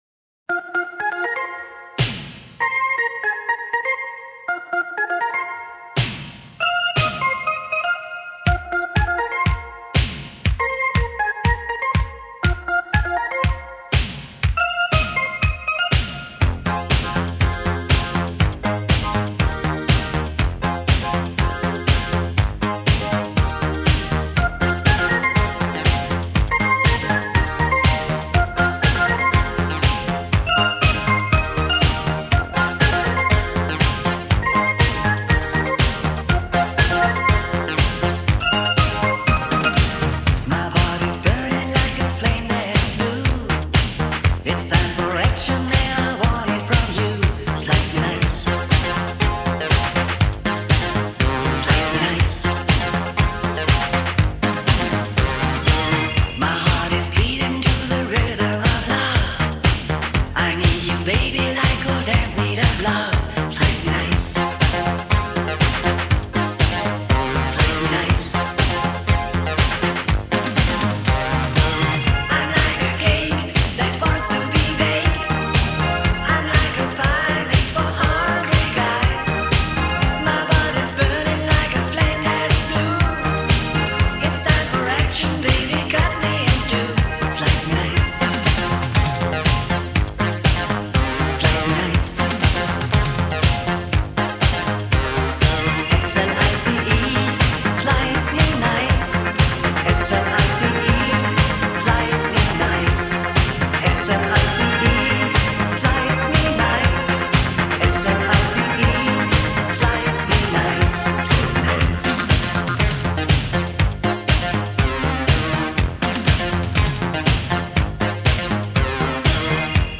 Genre: Electronic, Pop
Style: Italo-Disco, Europop, Disco